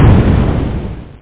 explosin.mp3